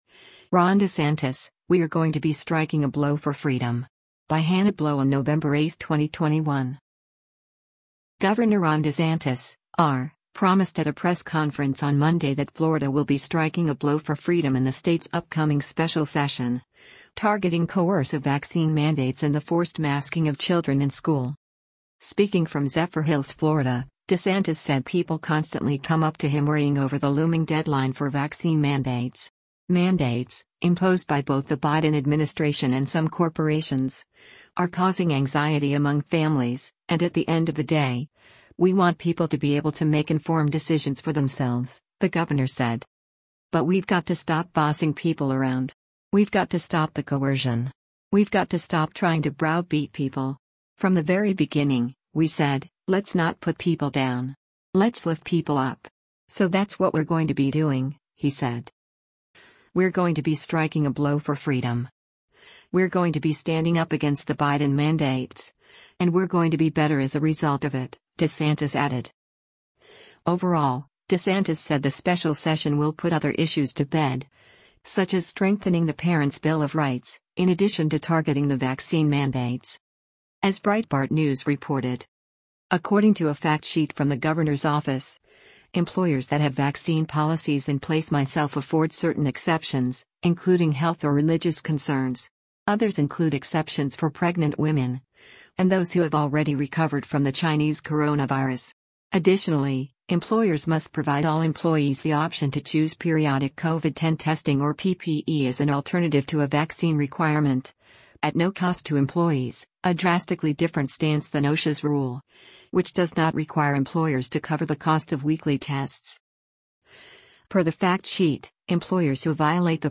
Gov. Ron DeSantis (R) promised at a press conference on Monday that Florida will be “striking a blow for freedom” in the state’s upcoming special session, targeting coercive vaccine mandates and the forced masking of children in school.